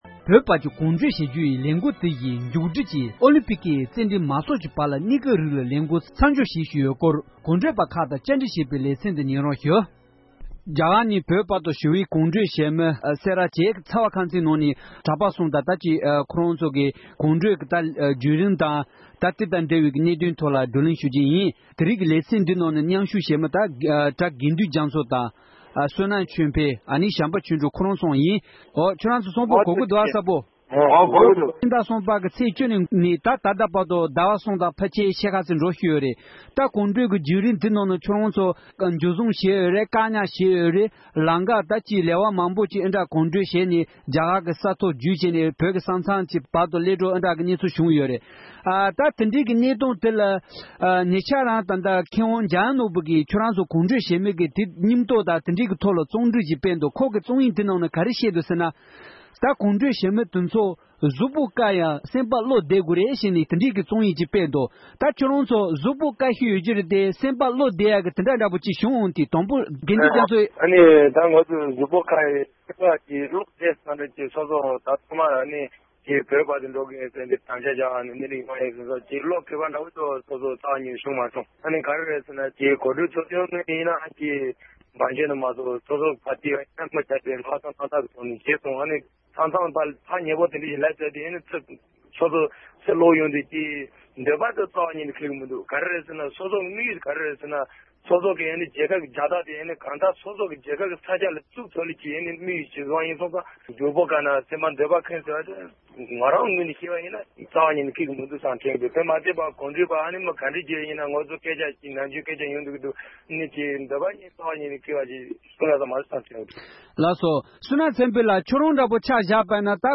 བཅའ་འདྲི་ཞུས་པ་ཞིག་གསན་རོགས་གནང༌༎